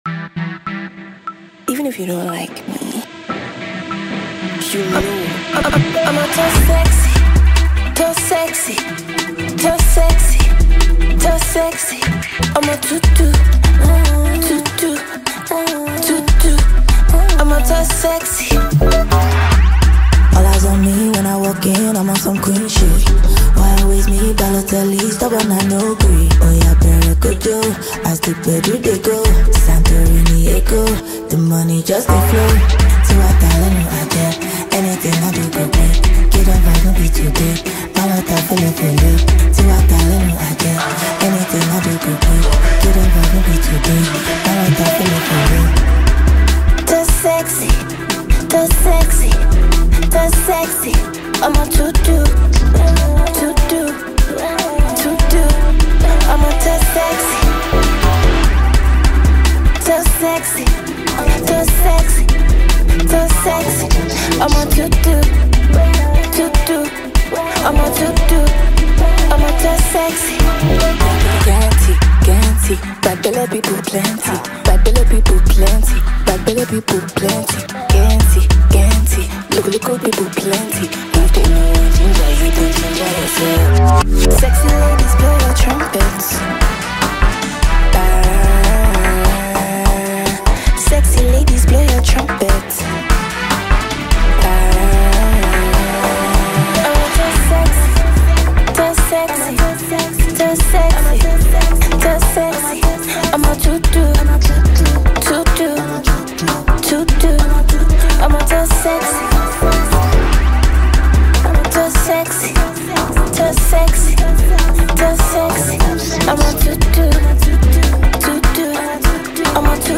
With its catchy hooks and confident energy